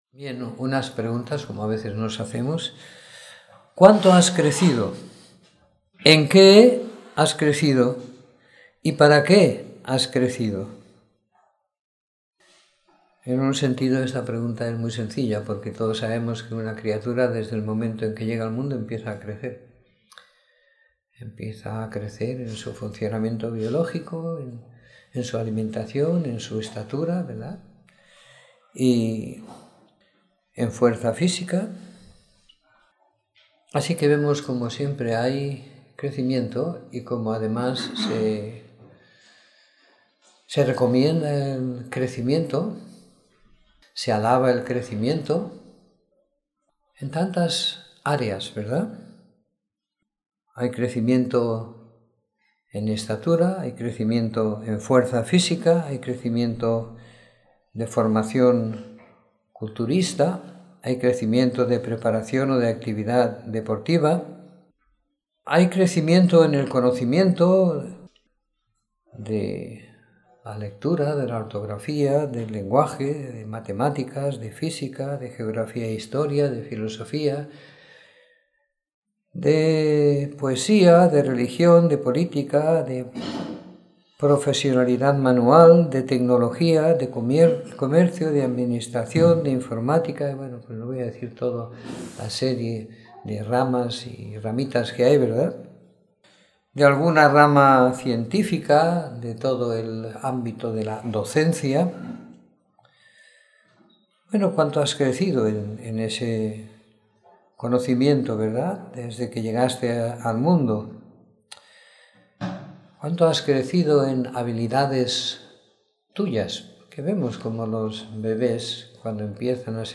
Reunión de Domingo por la Mañana